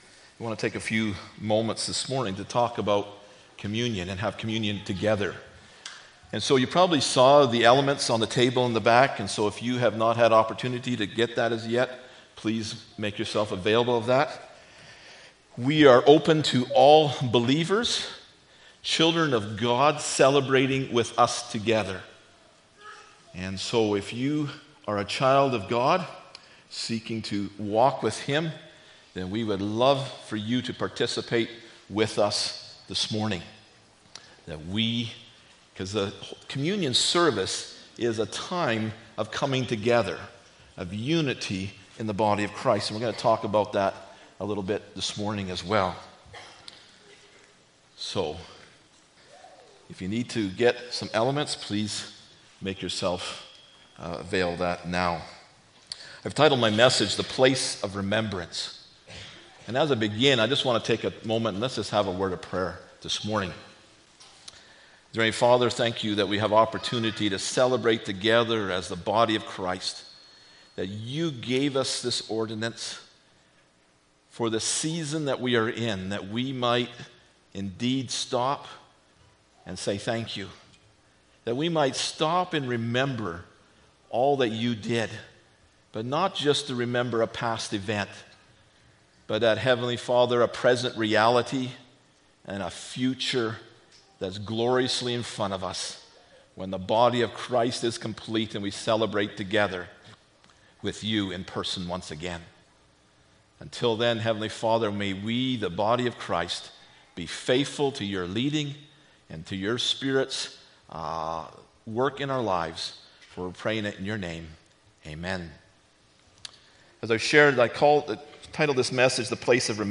1-20 Service Type: Sunday Morning Bible Text